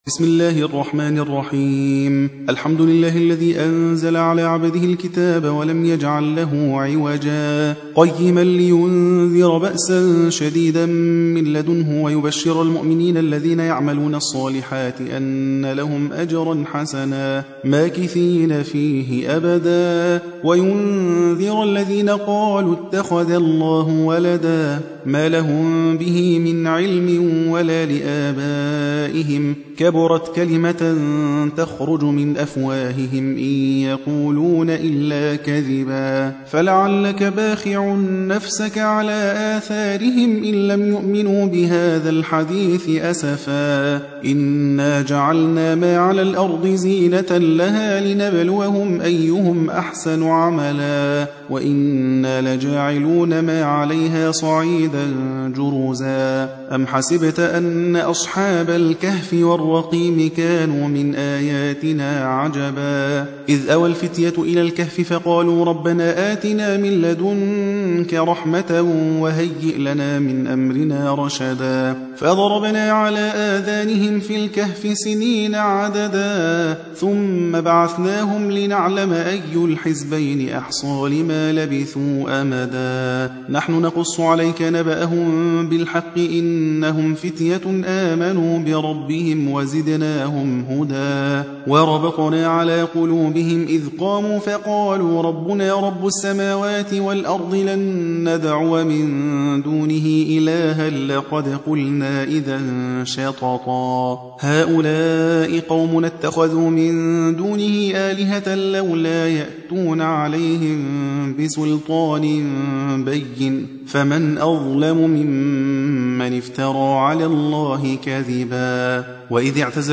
18. سورة الكهف / القارئ